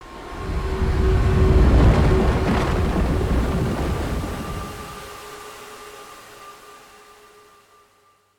В коллекции представлены различные аудиоэффекты: скрип дверей, жуткие звуки окружения, тематические мелодии – всё для полного погружения в атмосферу хоррора.
Звук возникновения Амбуша